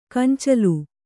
♪ kancalu